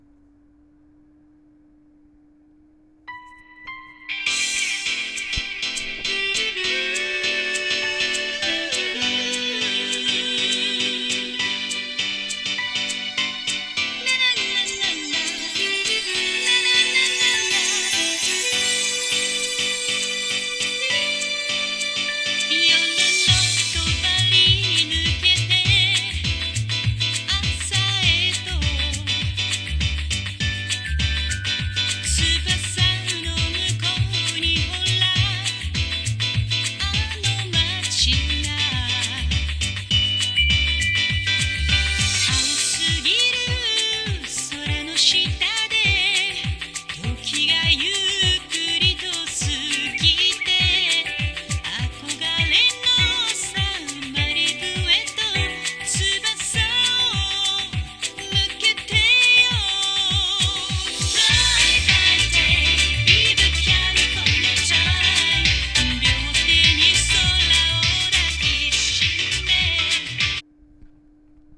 ・オリンパス ステレオICレコーダー LS-20M：リニアPCM 44.1kHz/16bit 無圧縮.WAV
価格帯から言っても一般的な音質で、可も無し不可も無しといった感じです。